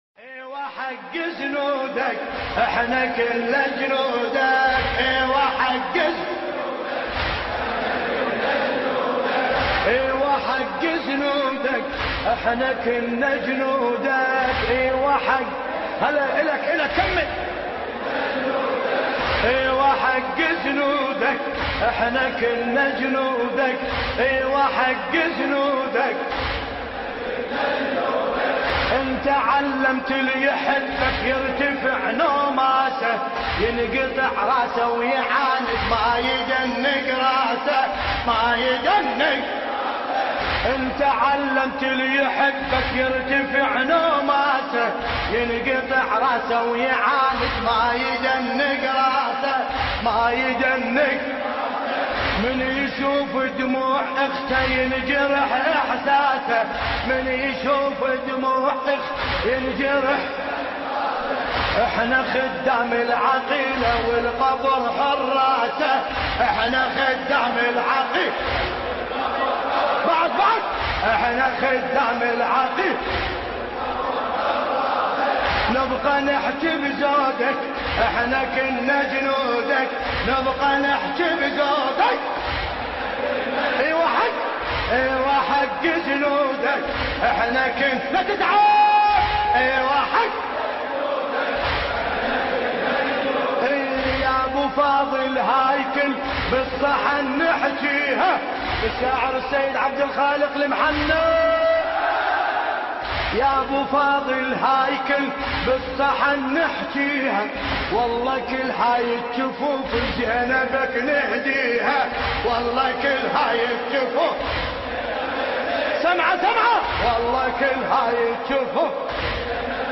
مداحی عربی بسیار زیبا